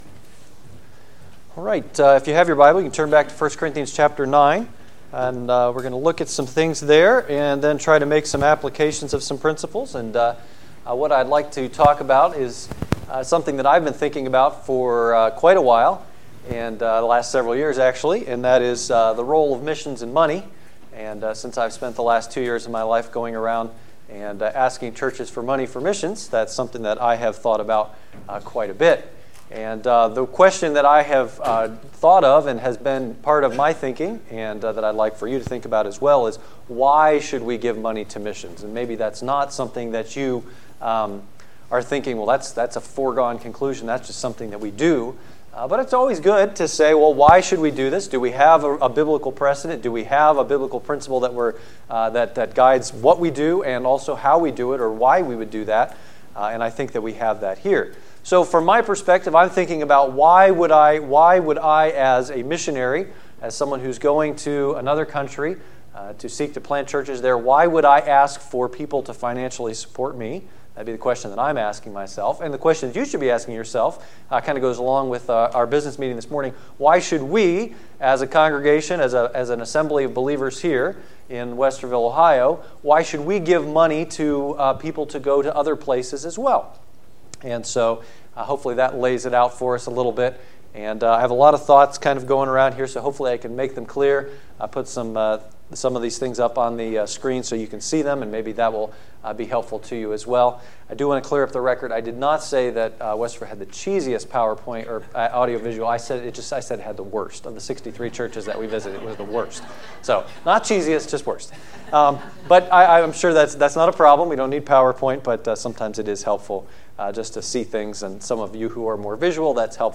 Service Sunday Evening